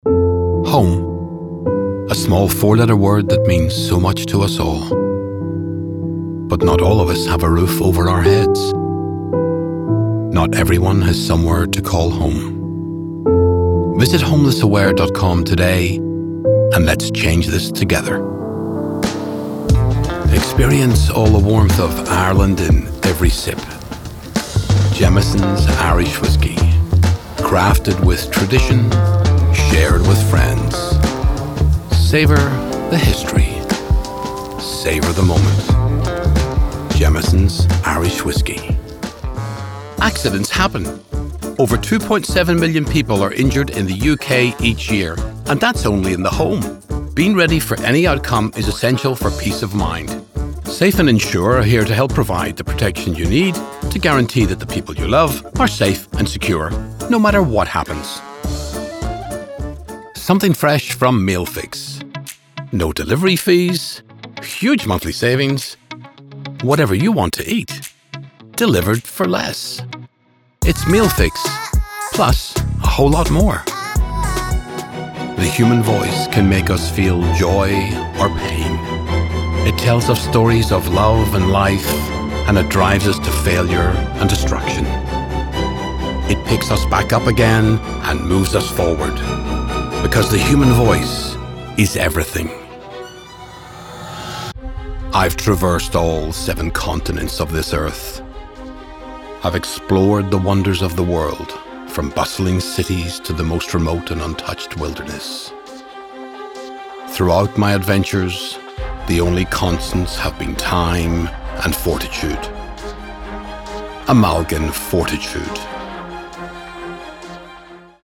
Male
40s/50s, 50+
Irish Northern Irish